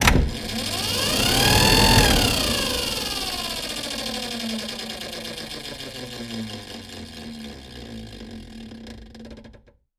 transporthangar.wav